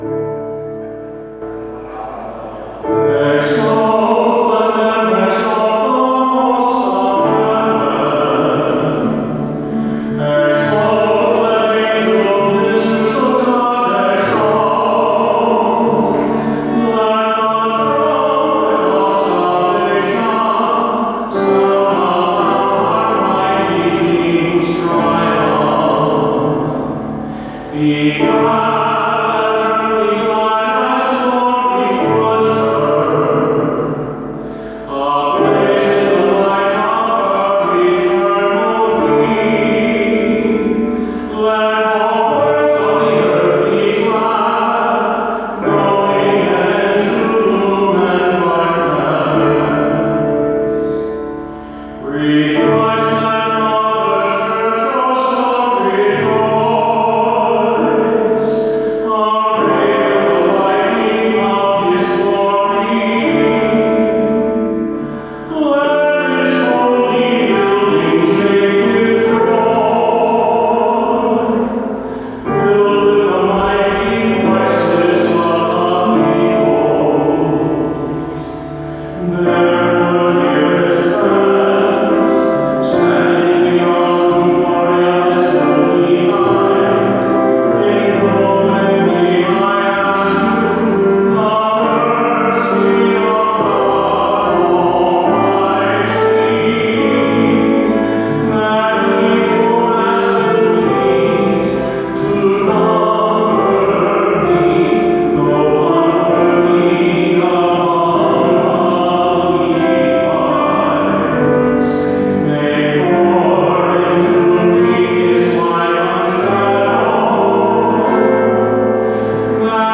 For the last 20 years, I have had the honor and privilege of singing the Easter Proclamation or The Exsultet. This is a true joy to pray on behalf of the Church at the start of the Easter Vigil.